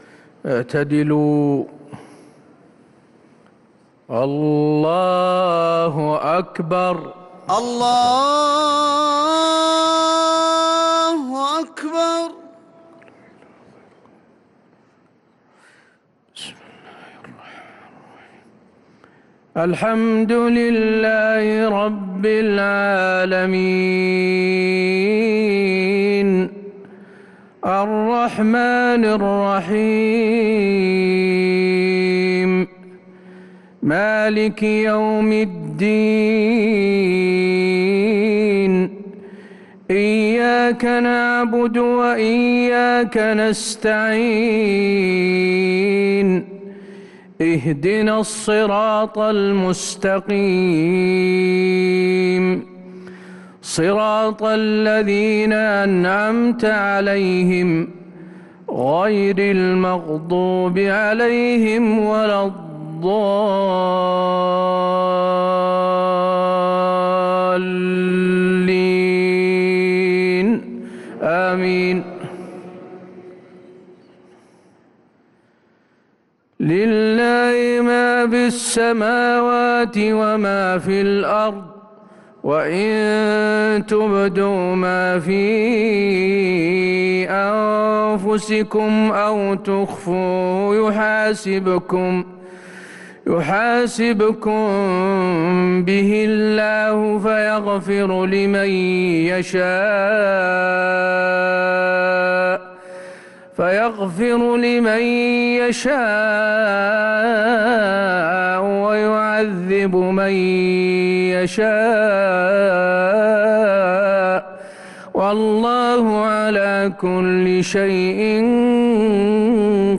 صلاة المغرب للقارئ حسين آل الشيخ 20 شوال 1443 هـ
تِلَاوَات الْحَرَمَيْن .